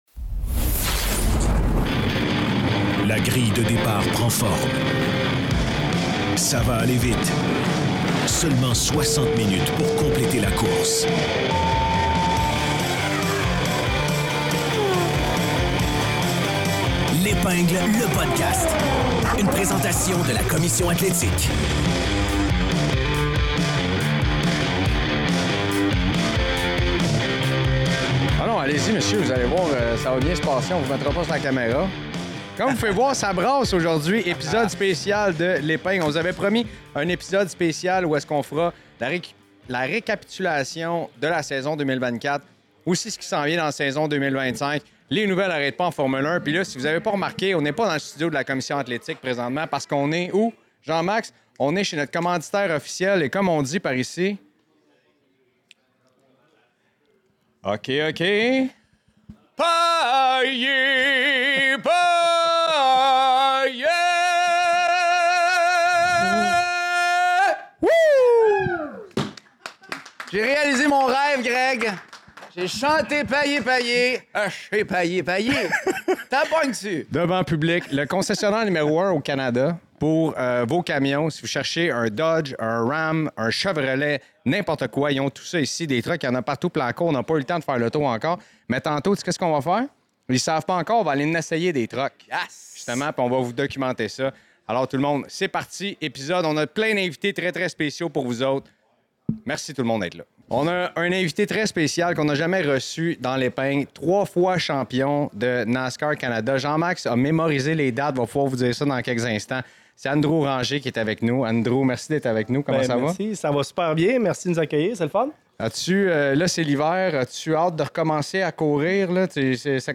L'Épingle Épisode spécial en direct du GM Paillé de Berthierville!